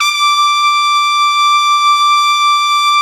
Index of /90_sSampleCDs/Best Service ProSamples vol.20 - Orchestral Brass [AKAI] 1CD/Partition A/VOLUME 002